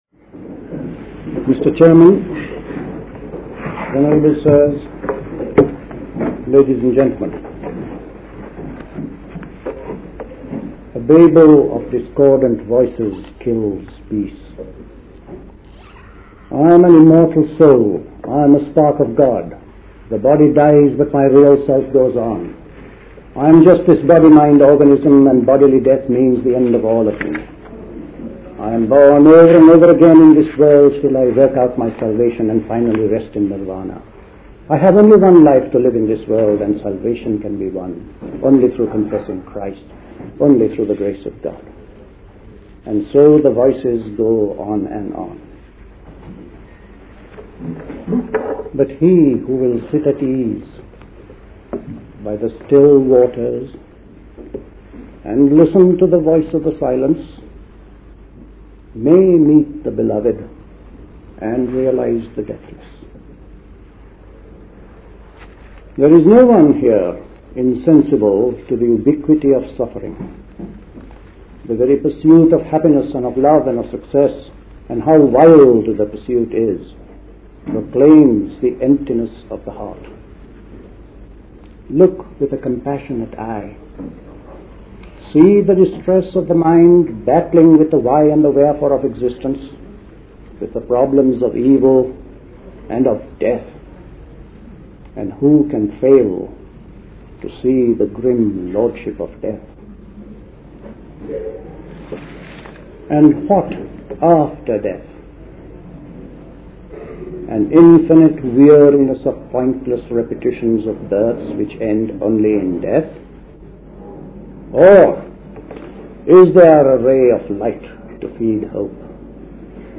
The Buddhist Society Summer School Talks